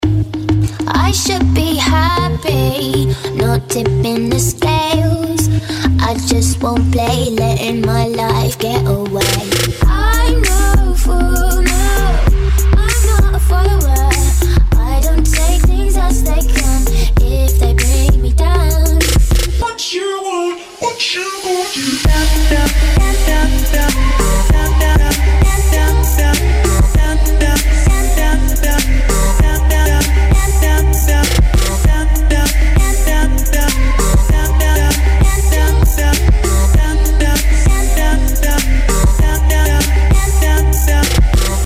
• Качество: 192, Stereo
мужской голос
женский вокал
dance
Electronic
EDM
Очень красивый трэп с женским вокалом